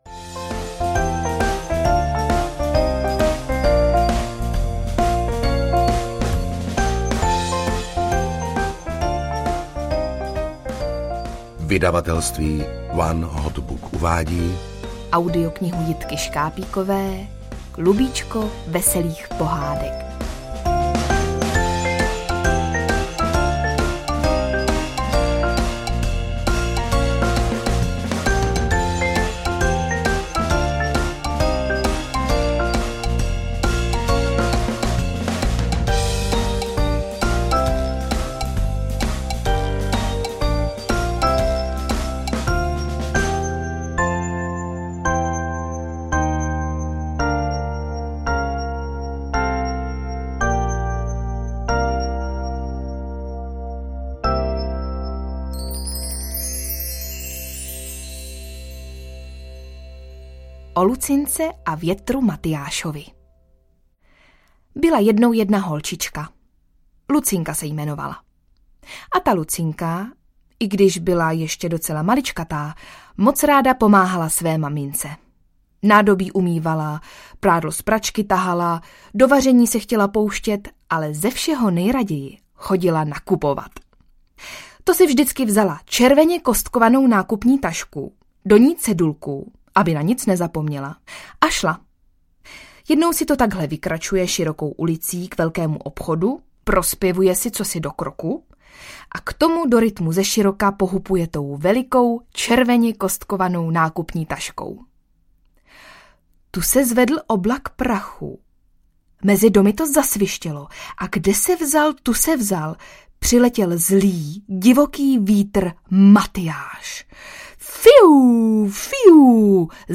Klubíčko veselých pohádek audiokniha
Ukázka z knihy
Příběhy, které čtou herci známí dětem z filmových pohádek, zavedou nejmenší posluchače mezi zvířátka, do vodní říše i větrného království.